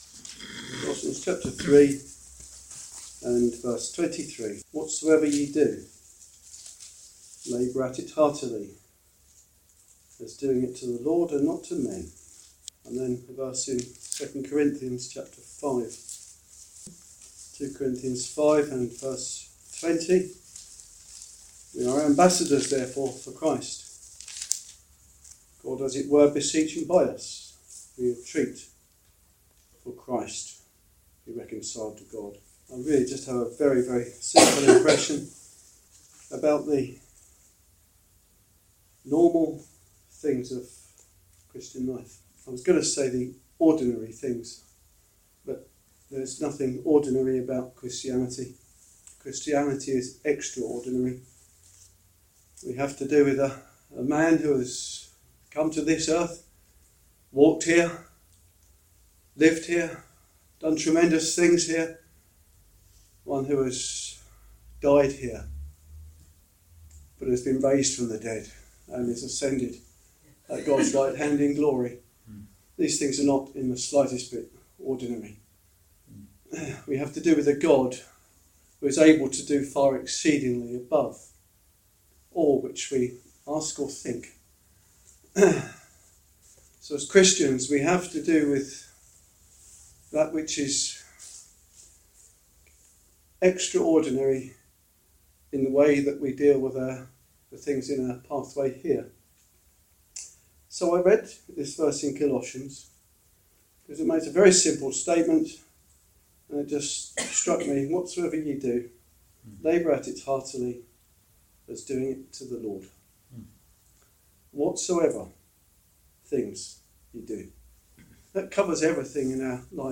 What does normal Christian life look like? In this short ministry word you will hear what the scriptures teach us about the normal things of Christianity.